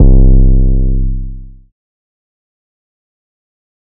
Metro Raw 808 (C).wav